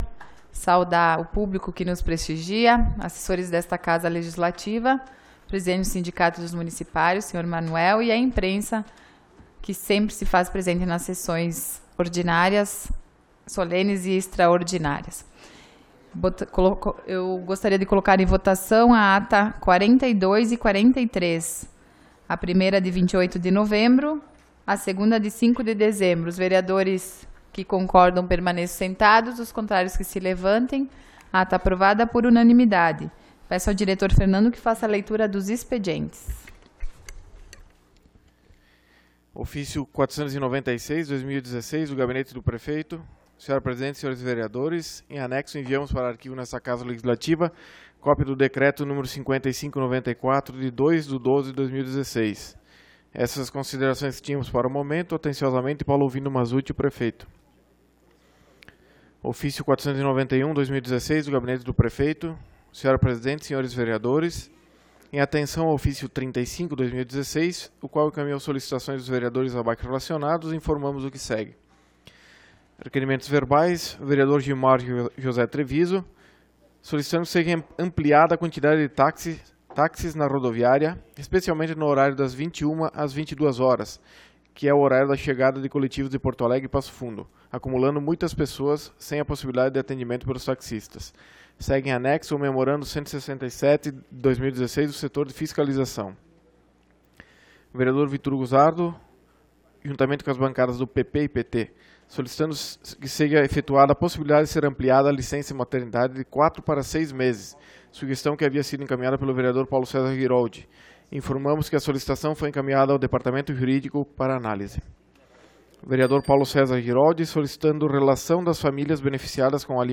Sessão Ordinária do dia 12 de Dezembro de 2016